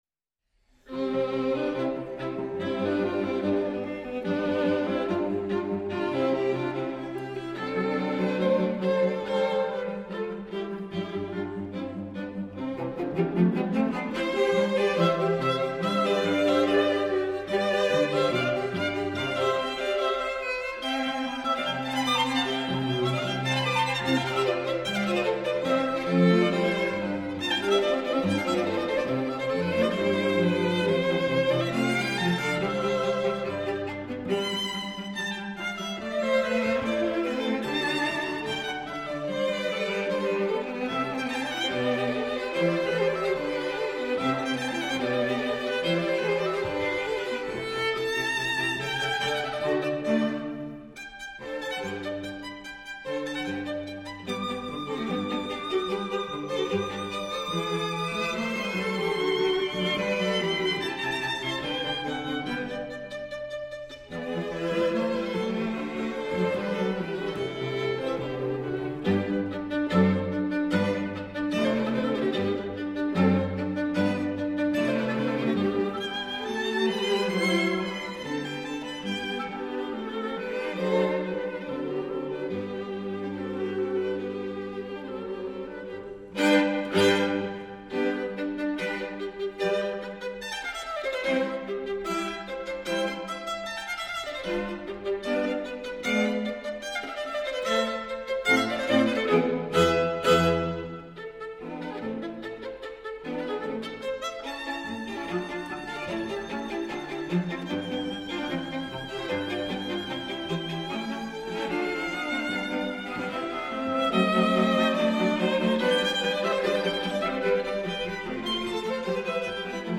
String Quartet in C major
Allegro